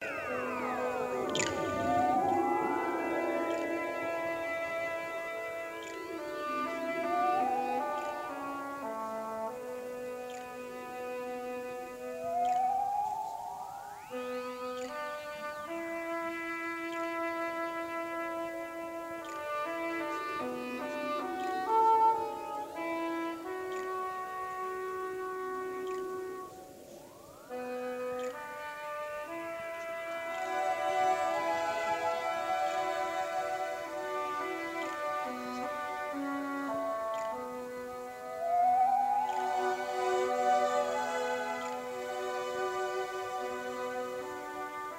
The complete symphonic score is presented in stereo